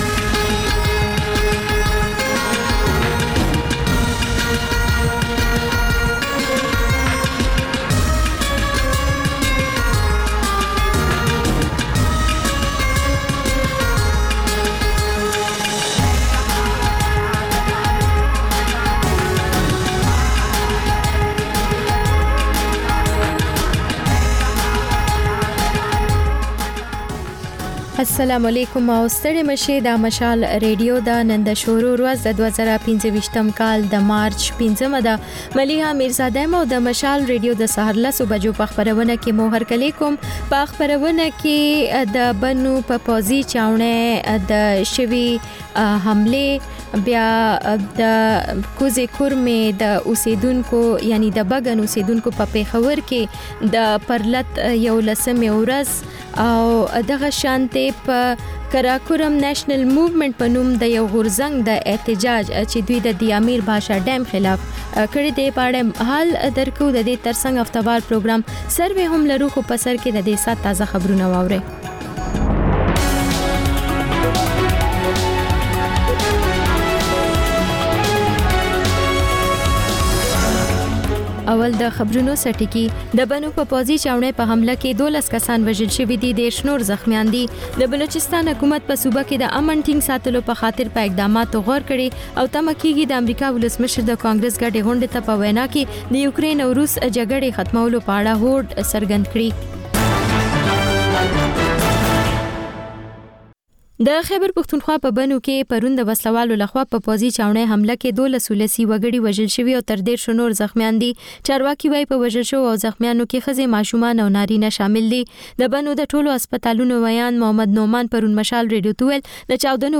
په دې خپرونه کې تر خبرونو وروسته بېلا بېل رپورټونه، شننې او تبصرې اورېدای شئ. د خپرونې په وروستۍ نیمايي کې اکثر یوه اوونیزه خپرونه خپرېږي.